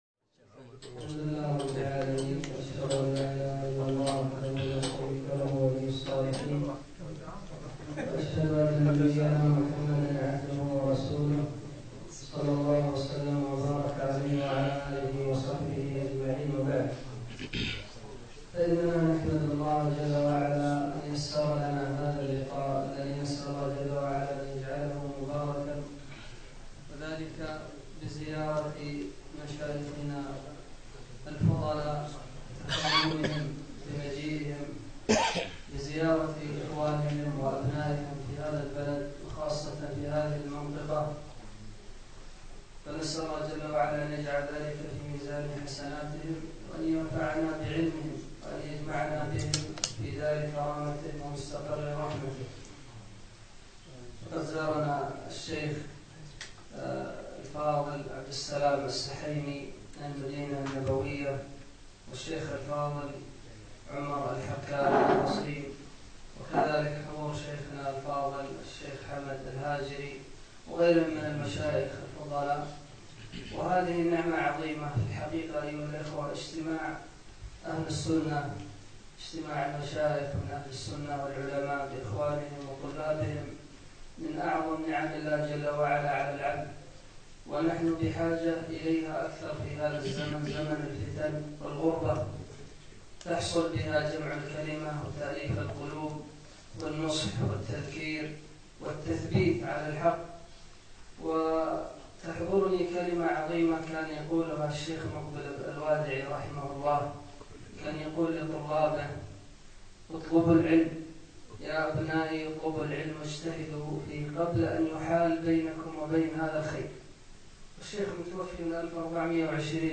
لقاء مفتوح مع ثلة من المشايخ الكرام بديوان شباب الدوحة والقيرون